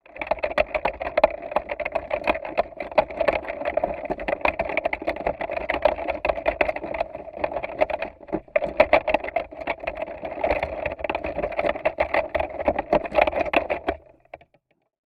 Edirol R09和压电式接触麦克风。